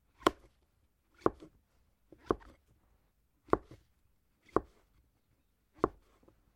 Звуки грибов
Звук нарезки шампиньонов на доске